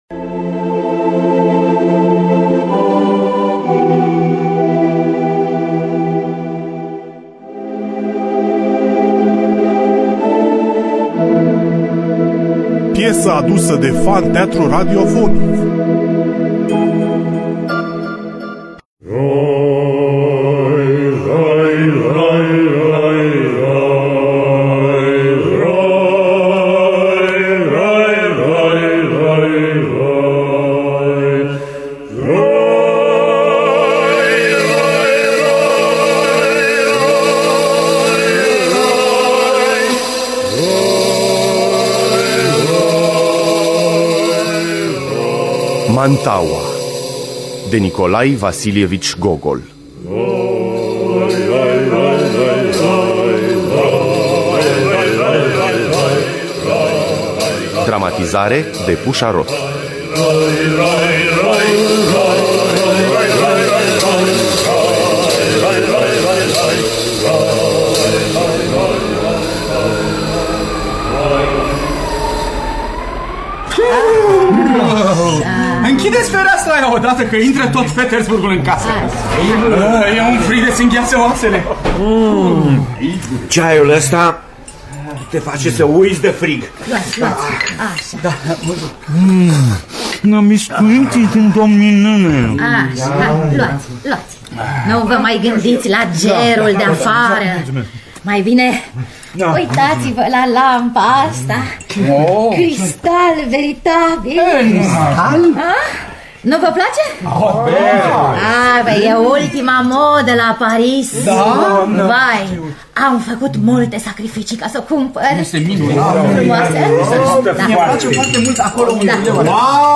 Nikolai Vasilievici Gogol – Mantaua (2004) – Teatru Radiofonic Online